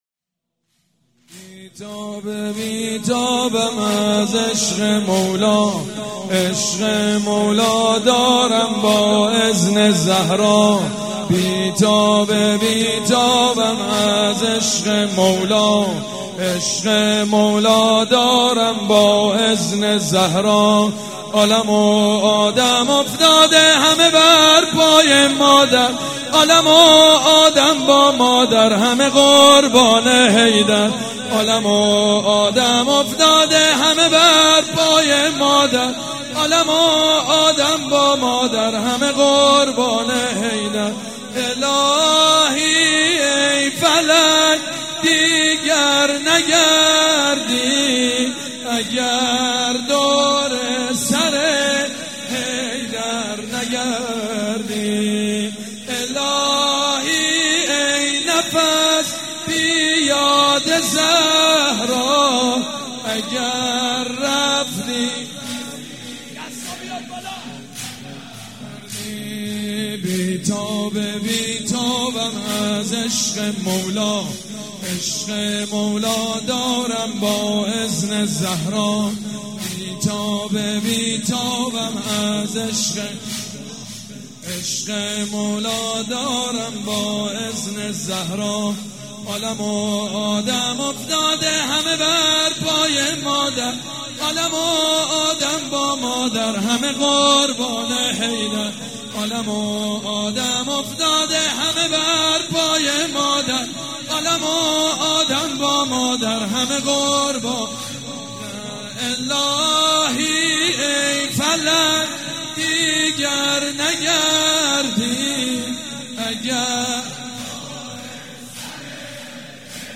شب هفتم رمضان95